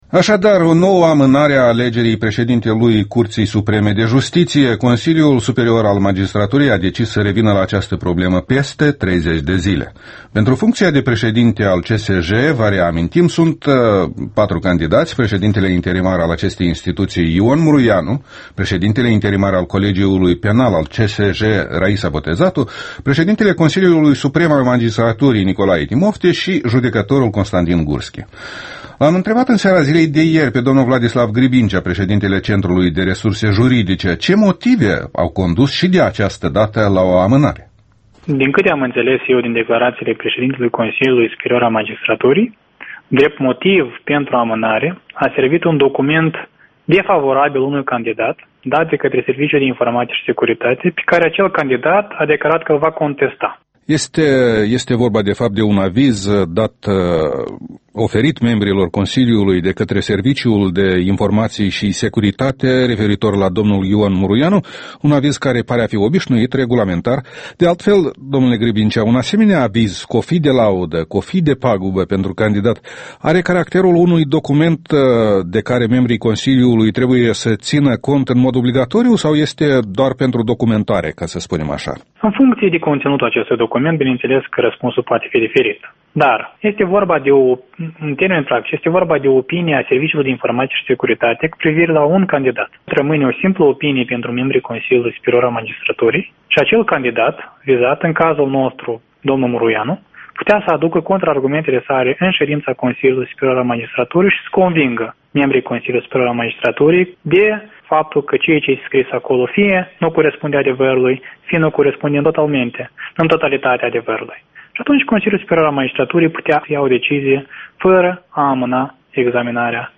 Interviul matinal EL: